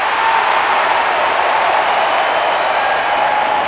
cheer2.wav